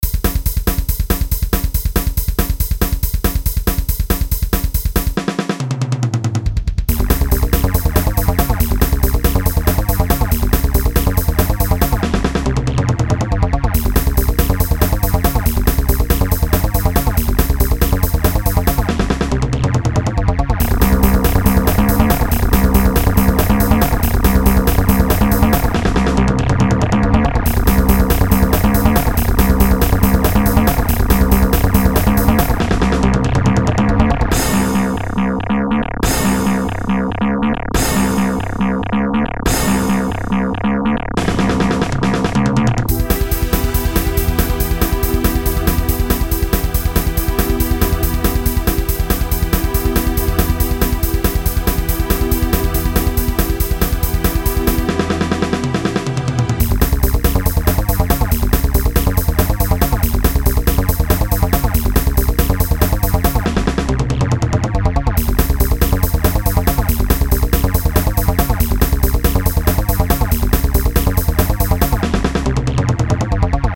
It sounds really flat..